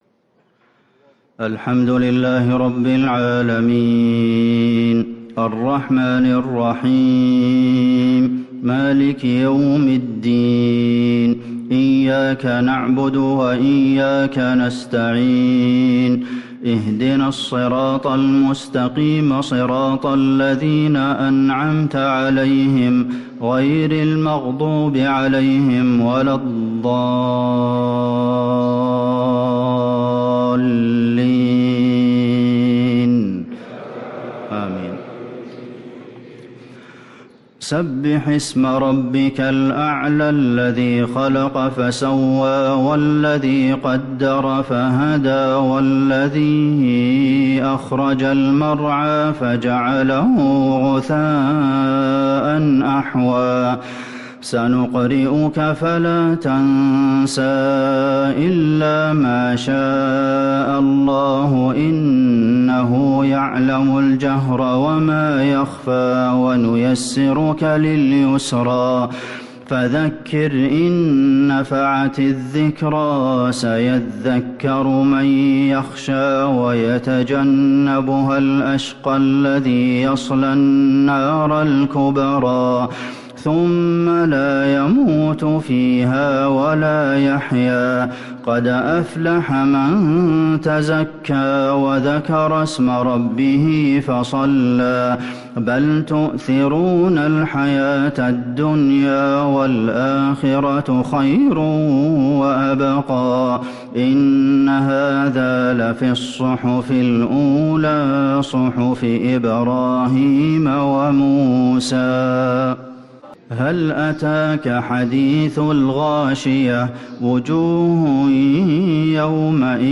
الإصدار القرآني المميز لشهر شعبان لعام ١٤٤٦هـ > سلسلة الإصدارات القرآنية الشهرية للشيخ عبدالمحسن القاسم > الإصدارات الشهرية لتلاوات الحرم النبوي 🕌 ( مميز ) > المزيد - تلاوات الحرمين